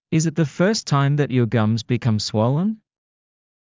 ｲｽﾞ ｲｯﾄ ｻﾞ ﾌｧｰｽﾄ ﾀｲﾑ ｻﾞｯﾄ ﾕｱ ｶﾞﾑｽﾞ ﾋﾞｶﾑ ｽｳｫｰﾚﾝ